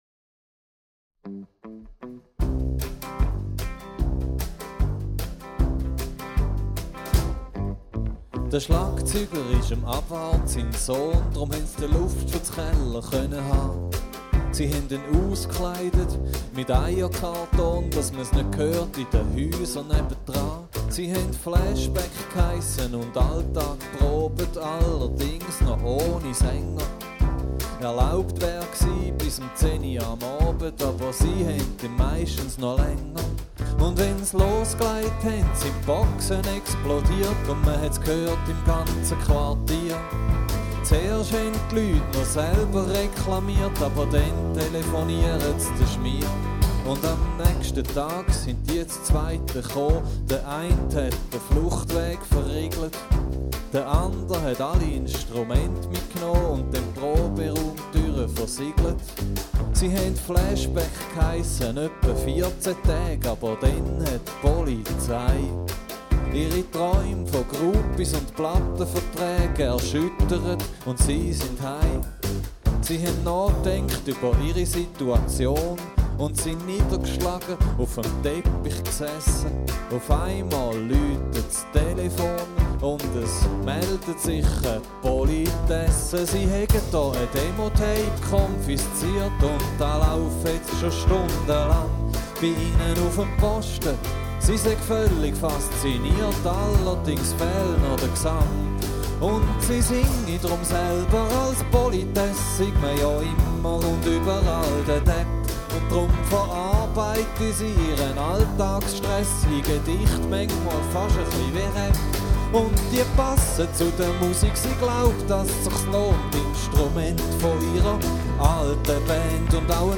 Wortwitz und Biomusik
Gitarre, Piano, Gesang
Perkussion, Gitarre, Gesang
mit akustischen Instrumenten einen dynamischen Sound